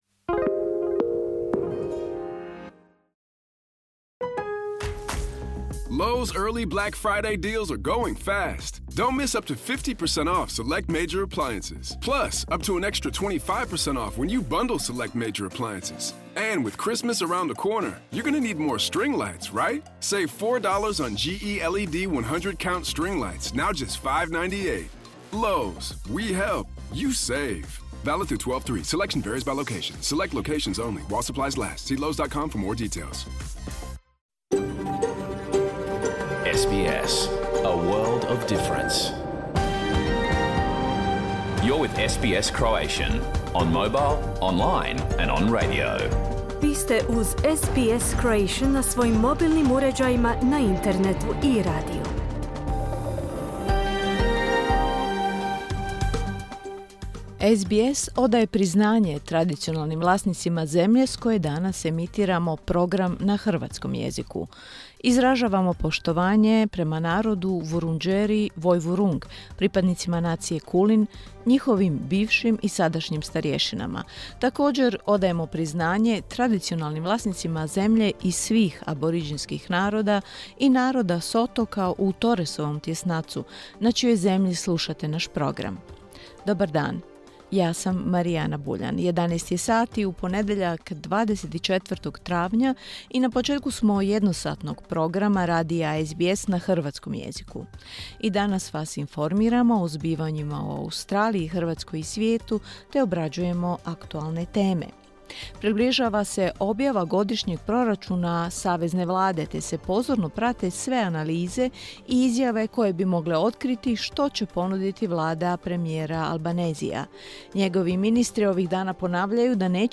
Pregled vijesti i aktualnih tema iz Australije, Hrvatske i ostatka svijeta. Program je emitiran uživo u ponedjeljak, 24. travnja 2023. u 11 sati.